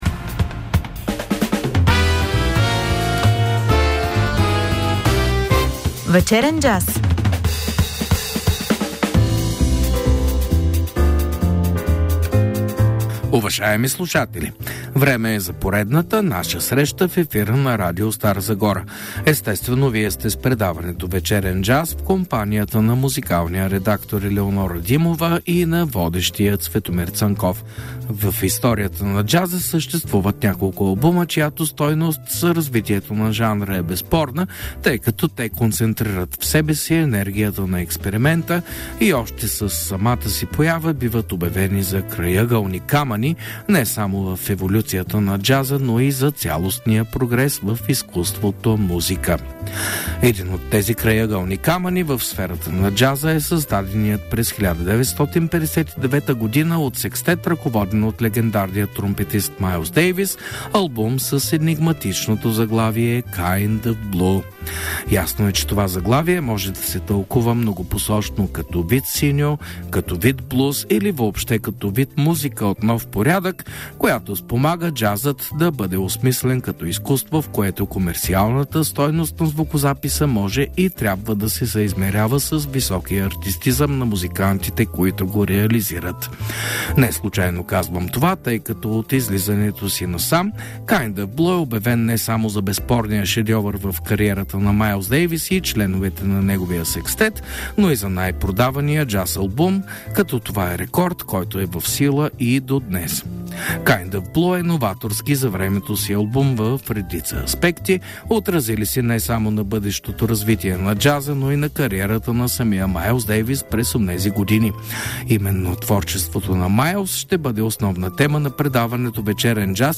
тромпетист